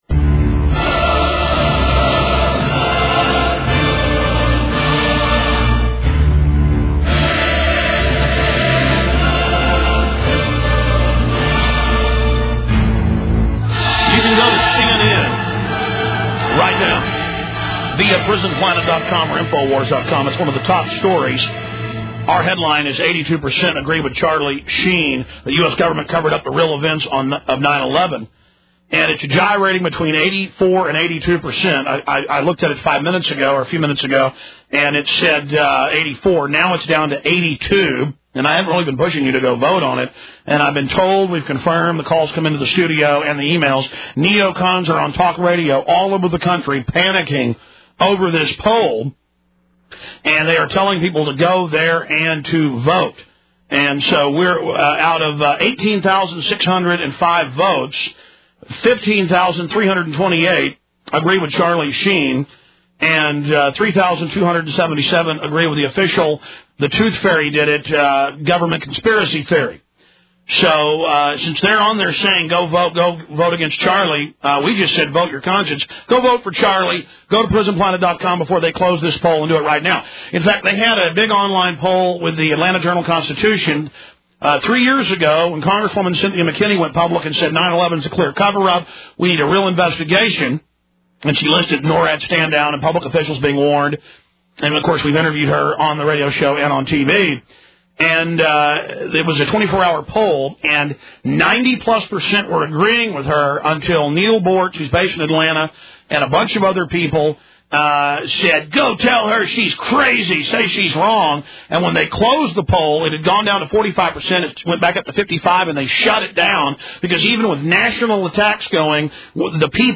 Check out the March 23rd 22 minute audio interview between Alex Jones and Charlie Sheen. 2.6 MB.